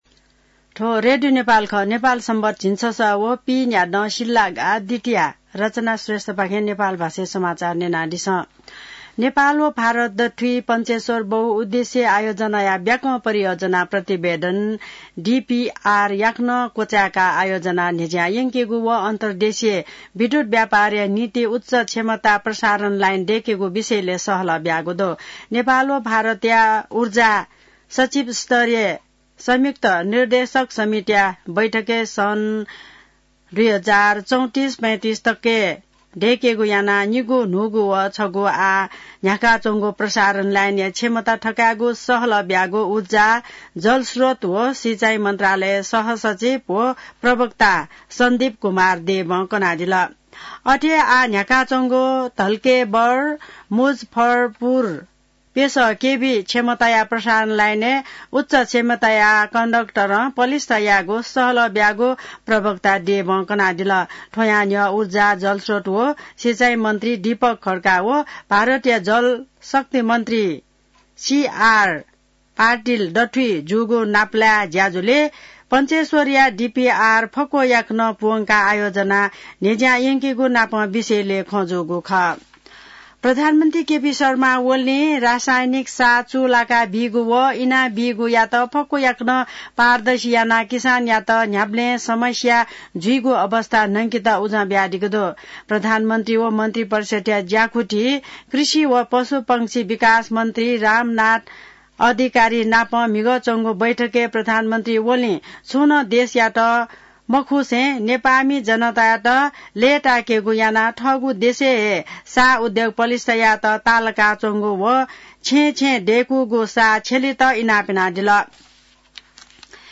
नेपाल भाषामा समाचार : ३ फागुन , २०८१